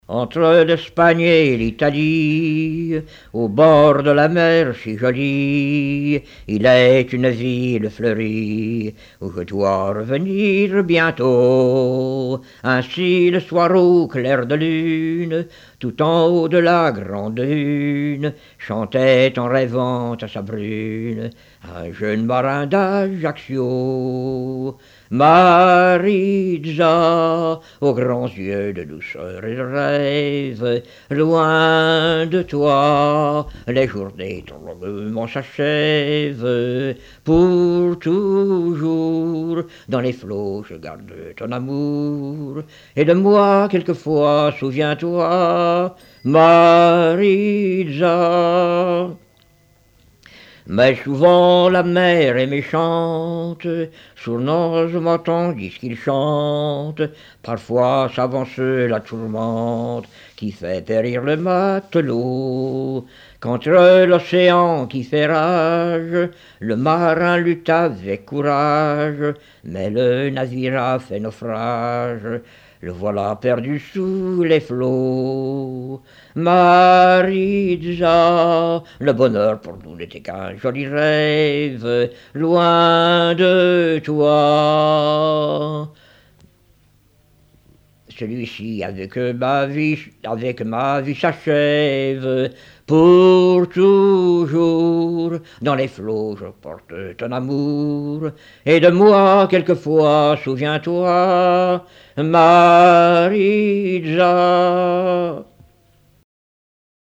Chansons du début XXe siècle
Pièce musicale inédite